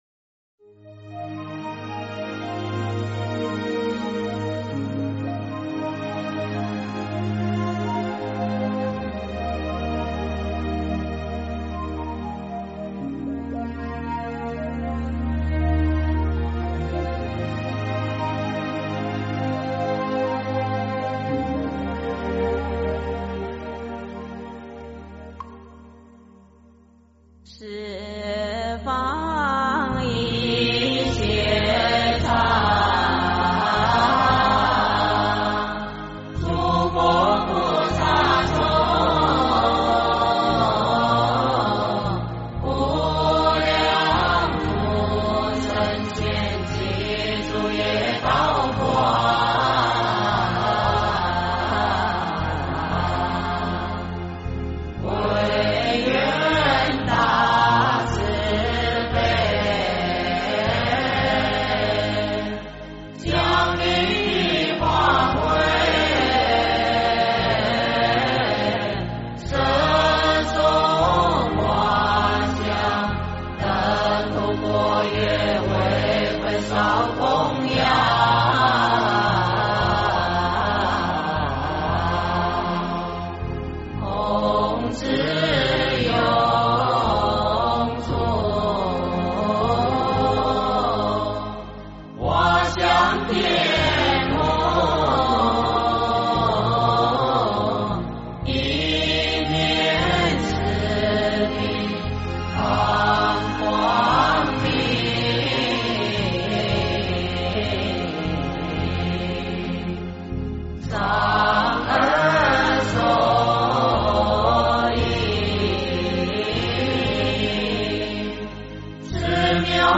十方一切刹 诵经 十方一切刹--如是我闻 点我： 标签: 佛音 诵经 佛教音乐 返回列表 上一篇： 般若波罗密多心经 下一篇： 虔诚贡献颂 相关文章 日光菩萨陀罗尼和月光菩萨陀罗尼--海涛法师 日光菩萨陀罗尼和月光菩萨陀罗尼--海涛法师...